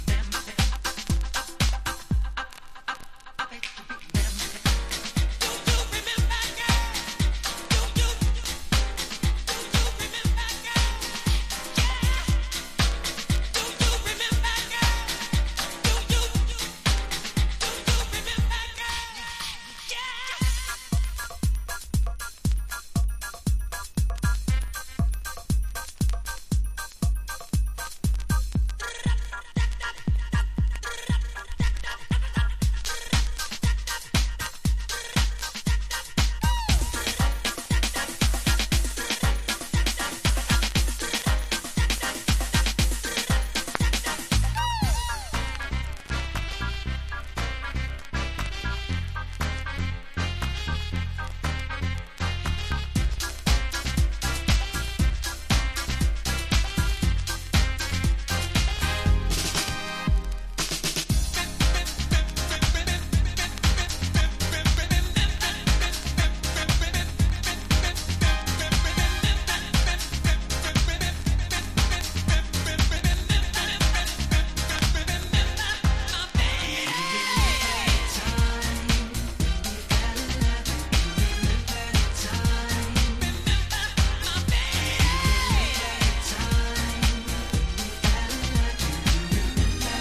• R&B